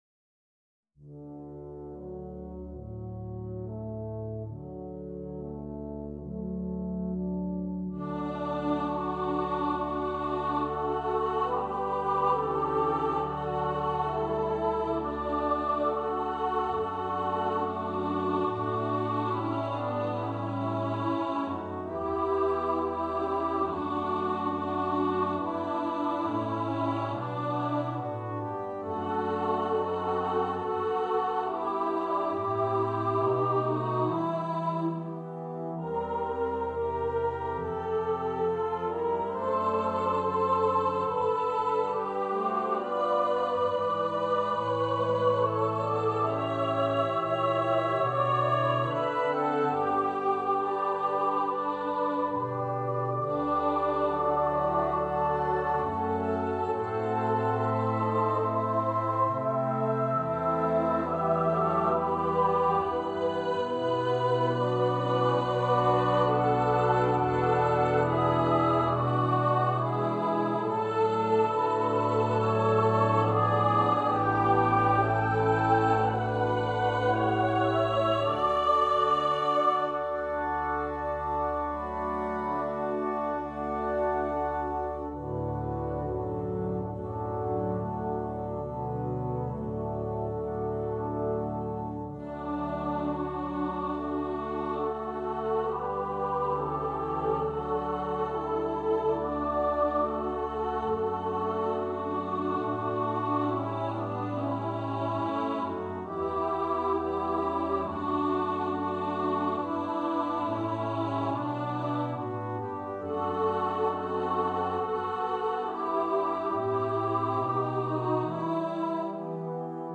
Besetzung: Children Choir & Brass Band